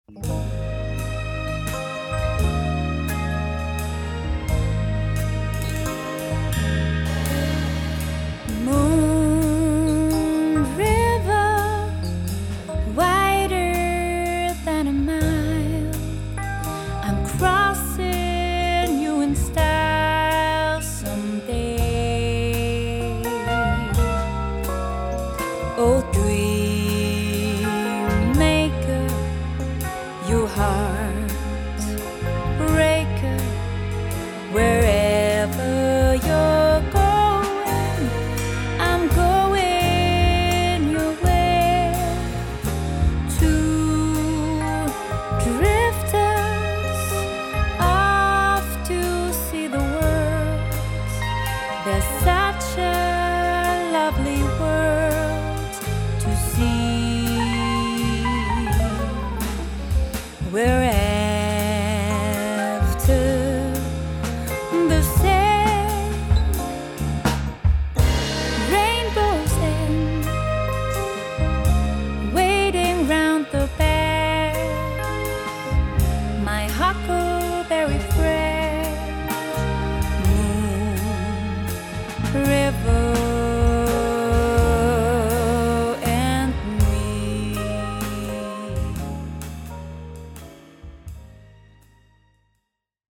Quartett bis Sextett